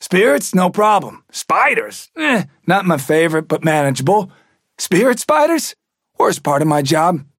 Trapper voice line - Spirits?